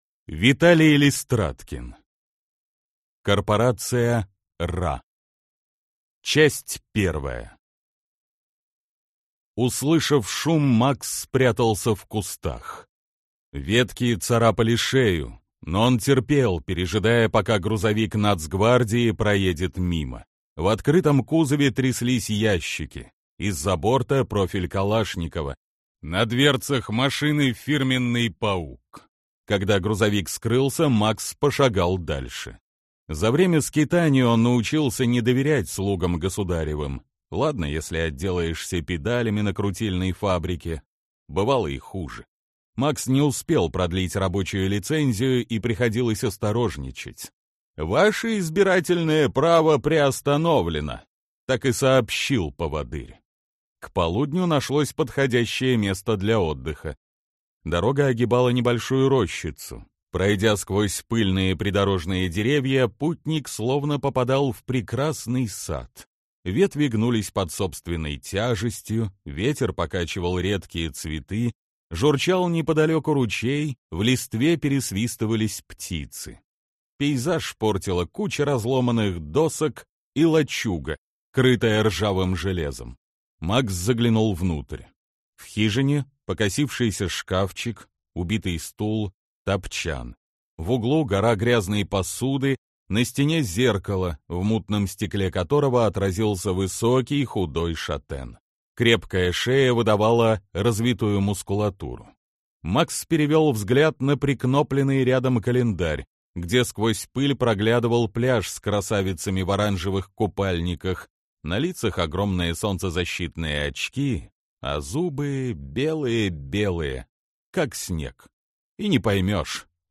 Аудиокнига Корпорация Ра | Библиотека аудиокниг